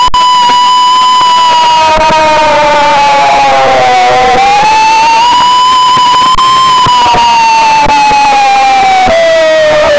aaaaaaaaa-running-dmb3p3xt.wav